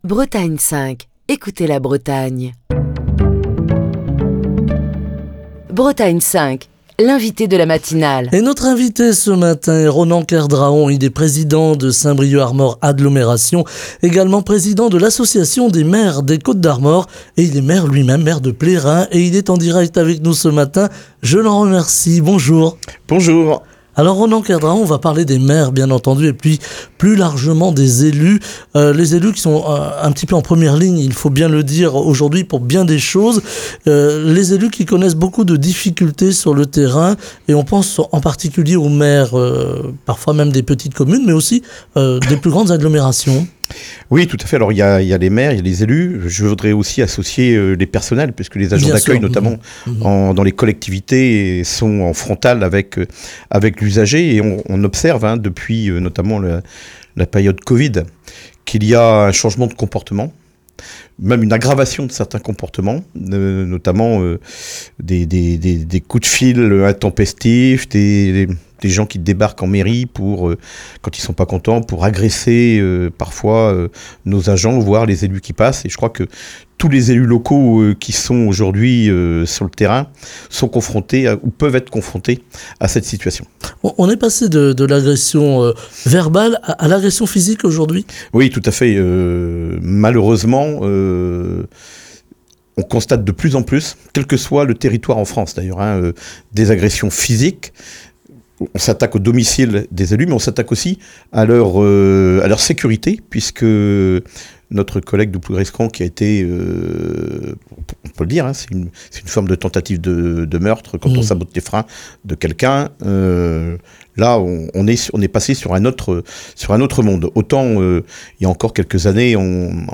Ce mercredi nous parlons des difficultés des élus de terrain que sont les maires, des problématiques fiscales ou territoriales rencontrées par les élus, des violences subies par les élus, de l'organistion du territoire et des compétences et de l'implication des élus, pour soutenir des projets, avec Ronan Kerdraon, président de Saint-Brieuc Armor Agglomération, président de l'association des maires des Côtes d'Armor et maire de Plérin, qui est l'invité de Bretagne 5 Matin.